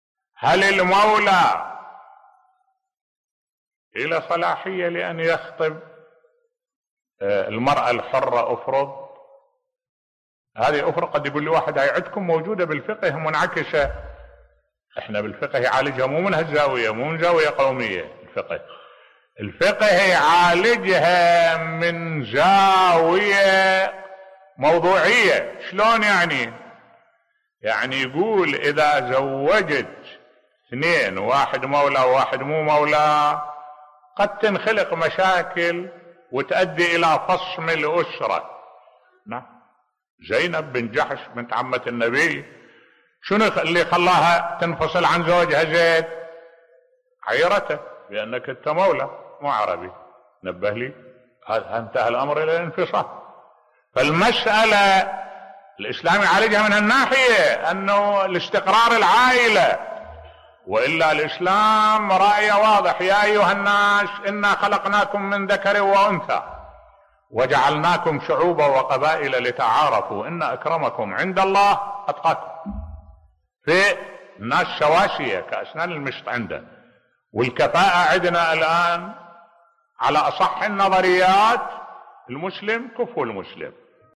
ملف صوتی زواج المولى من الحرة بصوت الشيخ الدكتور أحمد الوائلي